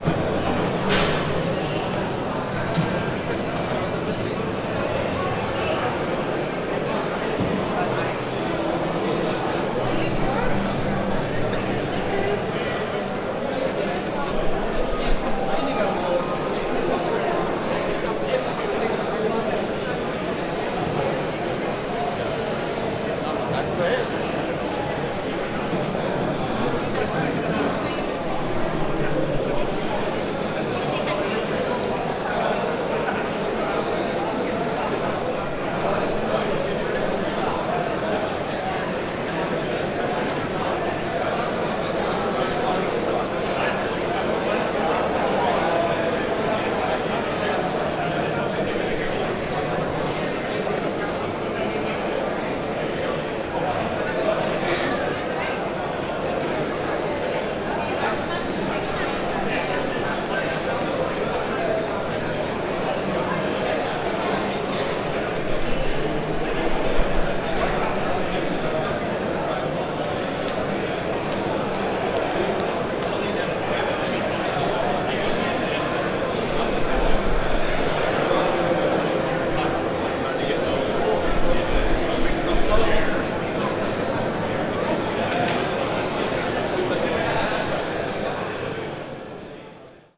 Este es el sonido de los pasillos de la feria más importante del móvil que se celebrará estos días en Barcelona.